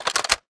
m82_magin.wav